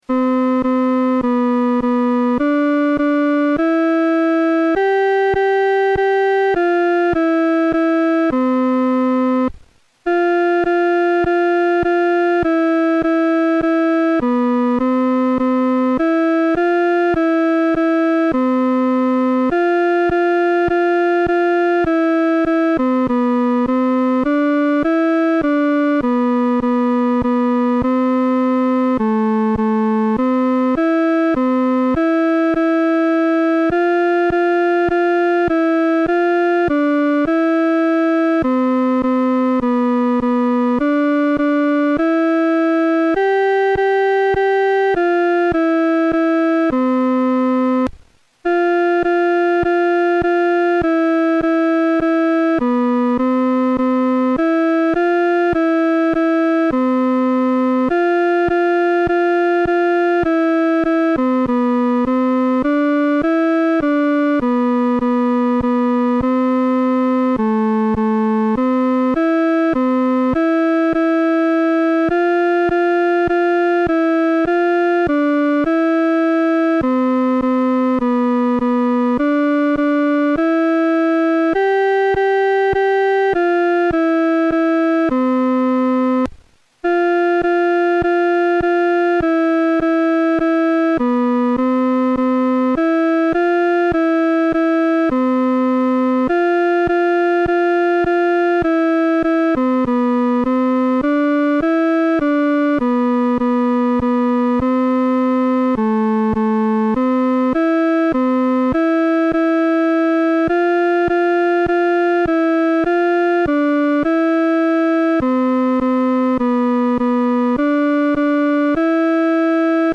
伴奏
女低
曲调开始是商调，非常有力，接着转为羽调，作了一个肯定；然后又转为宫调，有一个稳定的半终止。
这首圣诗的弹唱速度不宜太拖沓。